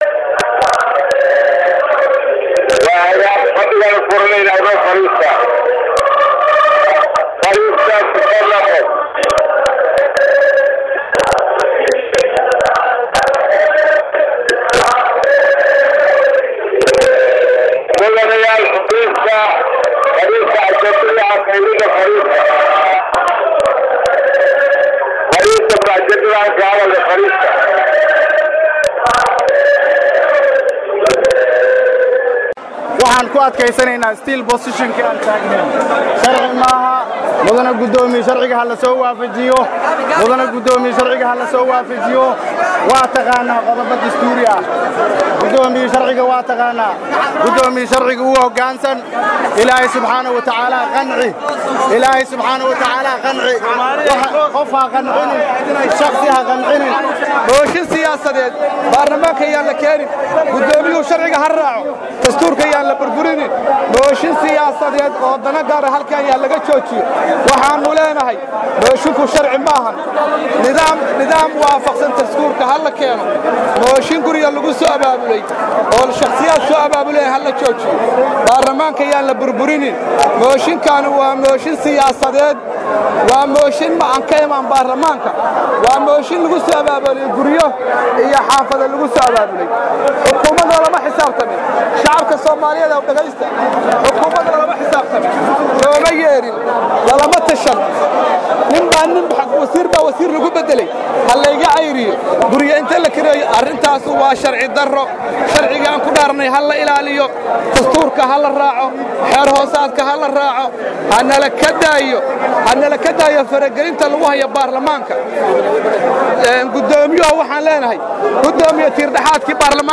Sabti, November 15, 2014 (HOL) — Kulankii baarlamaanka Somalia ee maanta ayaa mar kale furmi waayay, kaddib markii xildhibaannada mooshinka kasoo horjeeda ay billaabeen inay heesaan markii la doonayay in kulanka la furo.
Heesaha ay xildhibaannadu qaadayeen ayaa waxaa ka mid ahayd heesta Soomaaliyeey toosa, iyagoo diiday inay fariistaan, markii guddoonku ay ka dalbadeen inay heesta joojiyaan oo ay fariistaan.
DHAGEYSO: XILDHIBAANNO BUUQ KA KICIYAY BAARLAMAANKA